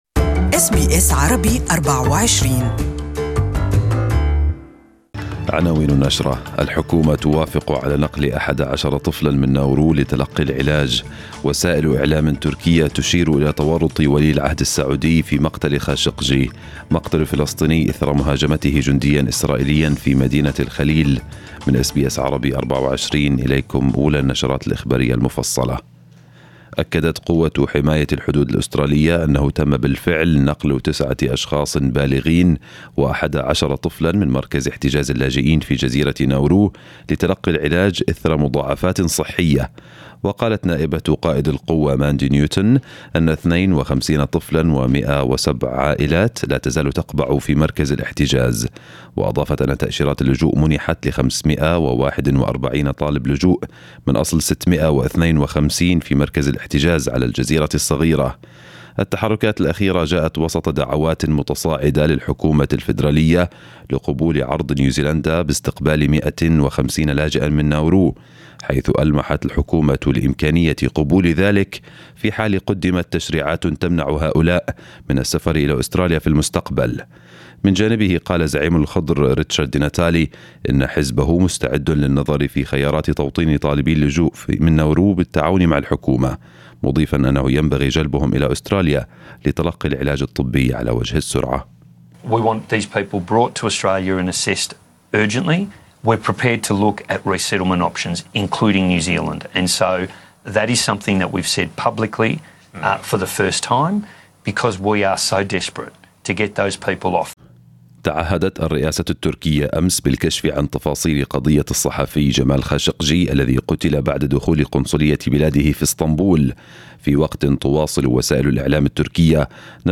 News bulletin for this morning